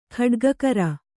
♪ khaḍgakara